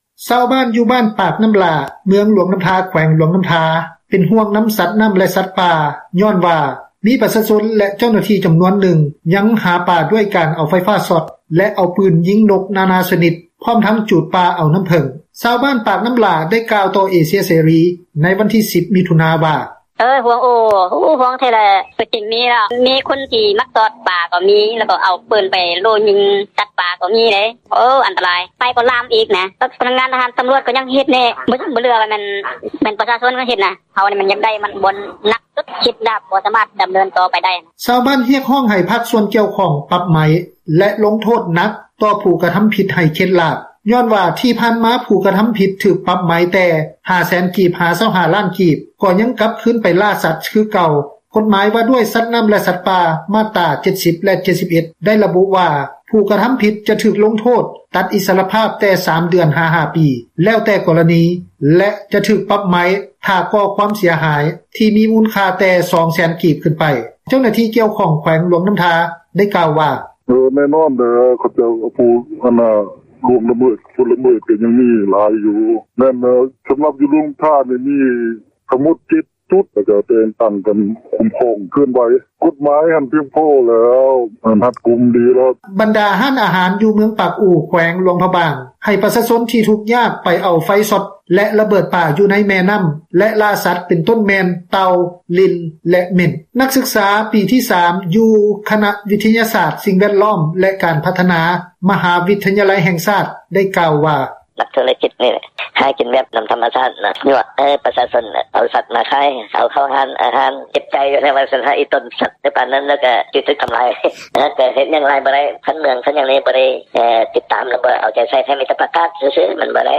ຊາວບ້ານ ບ້ານປາກນ້ຳຫລ້າ ເມືອງຫຼວງນ້ຳທາ ແຂວງຫຼວງນ້ຳທາ ເປັນຫ່ວງນຳສັດນ້ຳ ແລະສັດປ່າ ຍ້ອນວ່າມີປະຊາຊົນ ແລະເຈົ້າໜ້າທີ່ ຈຳນວນນຶ່ງ ຍັງຫາປາດ້ວຍການເອົາໄຟຟ້າຊອດ ແລະເອົາປືນໄປ ຍິງນົກນາໆຊນິດ ພ້ອມທັງຈູດປ່າເອົານ້ຳເຜິ້ງ ຊາວບບ້ານປາກນ້ຳຫຼ້າ ໄດ້ກ່າວຕໍ່ເອເຊັຽເສຣີ ໃນວັນທີ 10 ມິຖຸນາ ວ່າ: